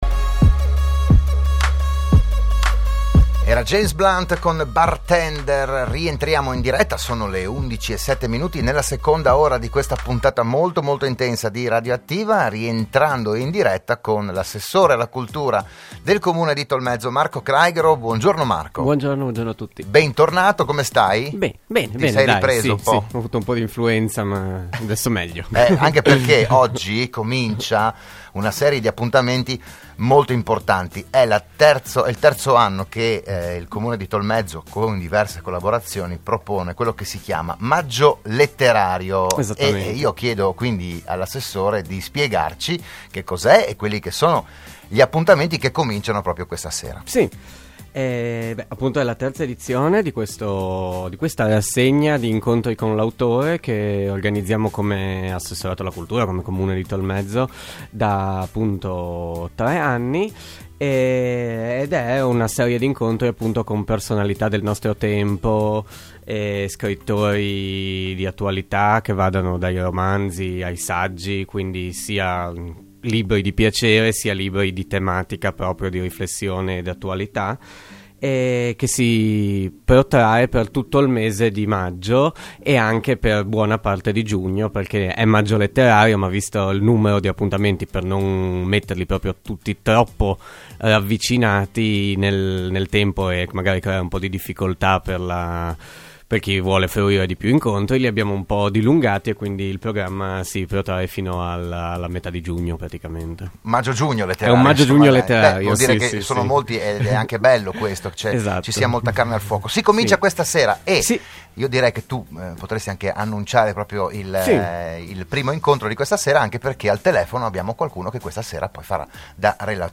Il PODCAST dell'intervento a Radio Studio Nord dell'assessore Marco Craighero